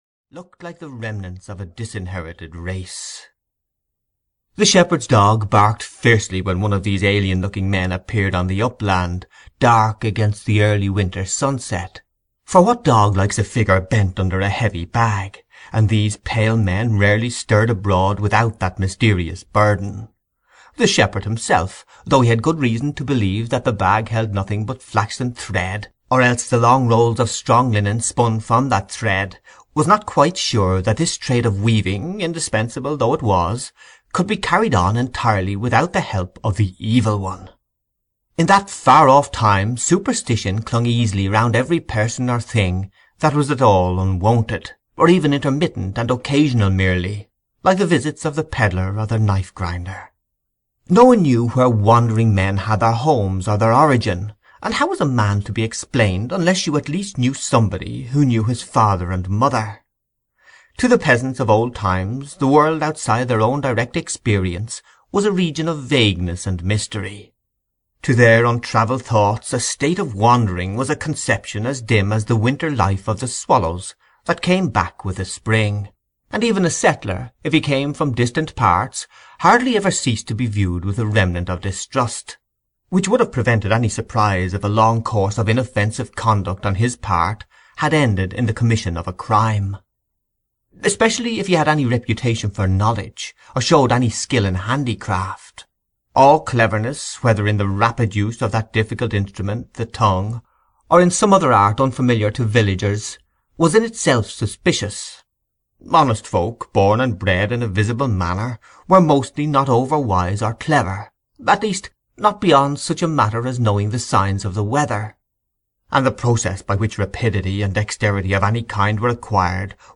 Silas Marner (EN) audiokniha
Ukázka z knihy